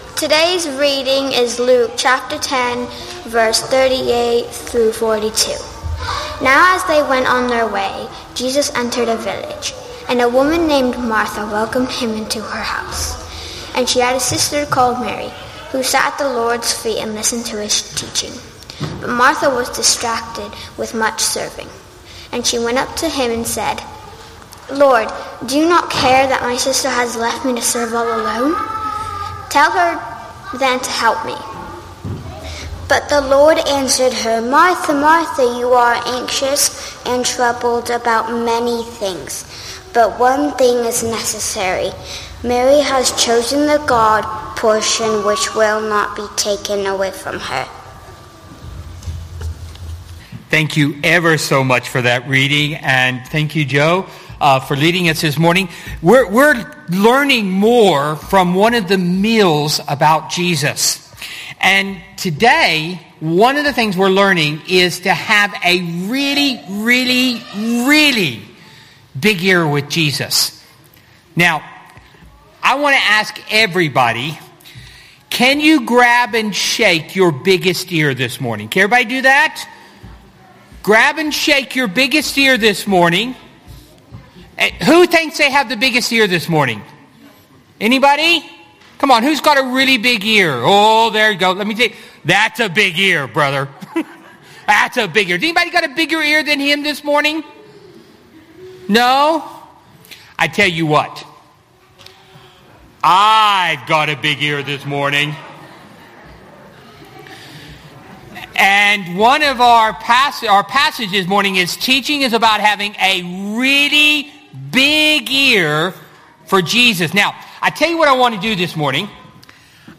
Luke 10:38-42; 31 January 2021, Morning Service. Sermon Series: Meals with Jesus - Family Services 10th January to 7th March 2021.